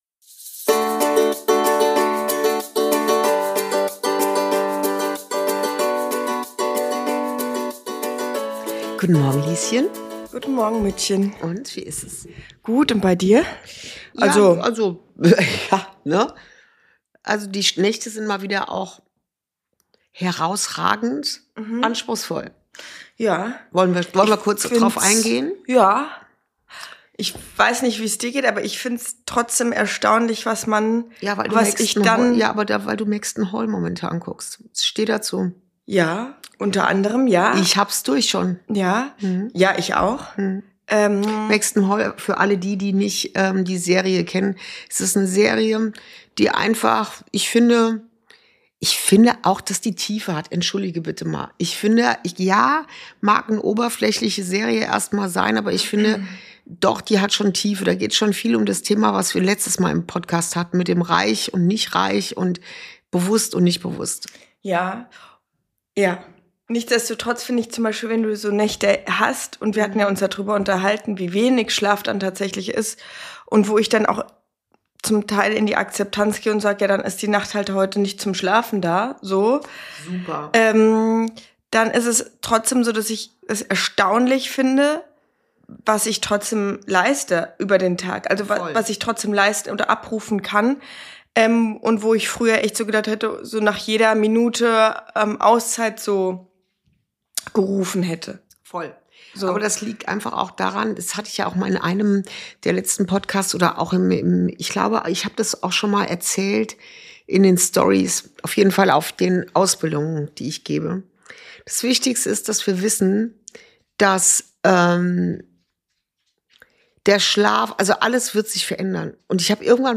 Folge 41: Transformation als natürlicher Prozess – Wenn Körper und Natur anfangen zu sprechen ~ Inside Out - Ein Gespräch zwischen Mutter und Tochter Podcast